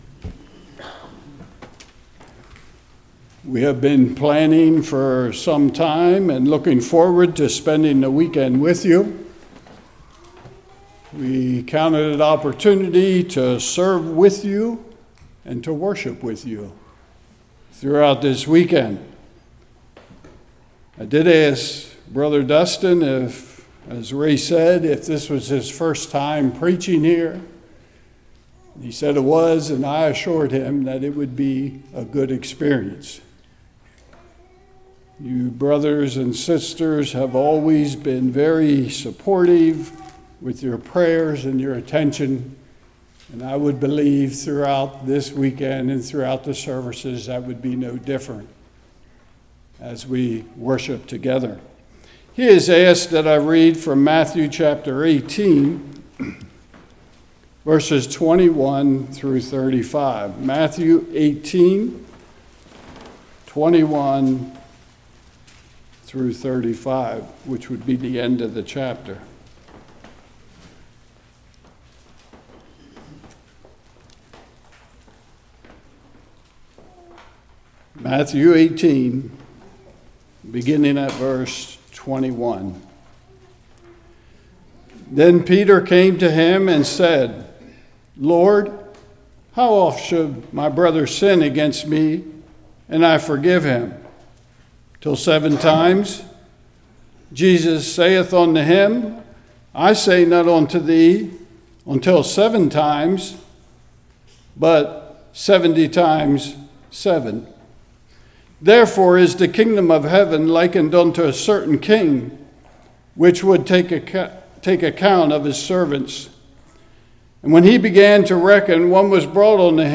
Series: Fall Lovefeast 2021
Service Type: Evening